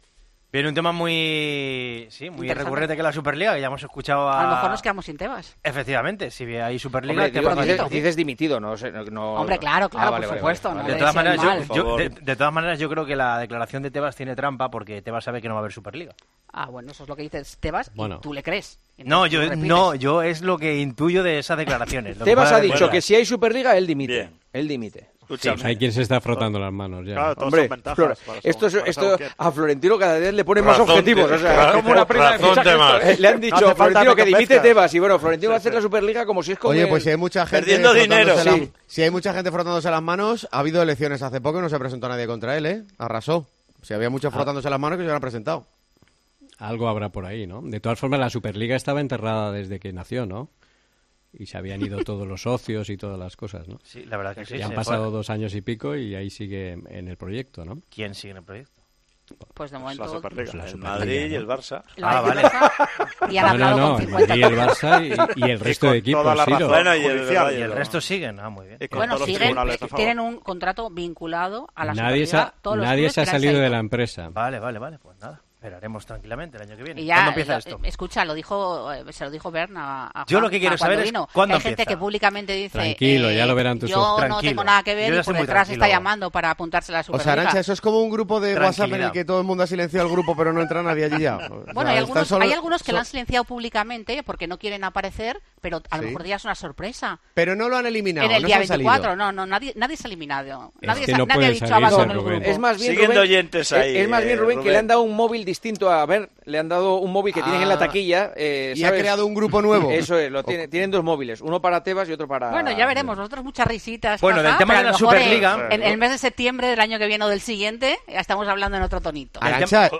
Entre risas, Juanma Castaño habló sobre las palabras del presidente de LaLiga en El Partidazo de COPE.